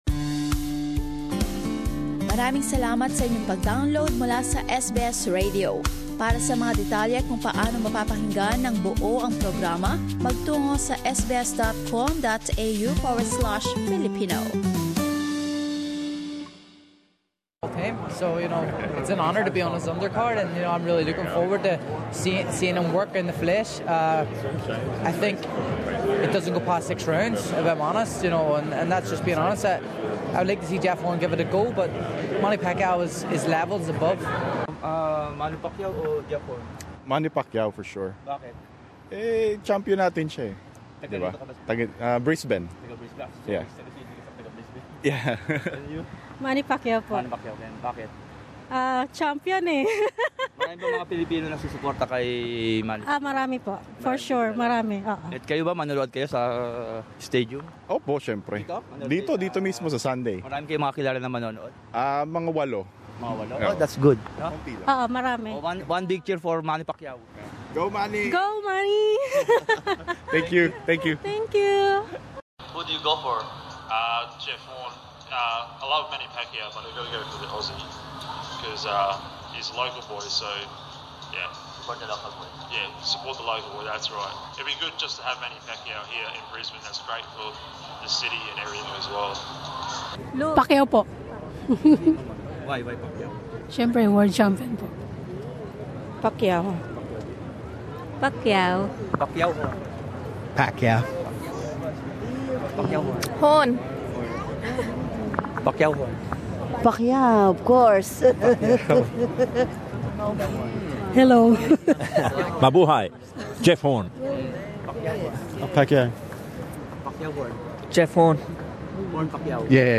Short clip of excited Filipinos showing support to Pacquiao few hours before the big fight: Share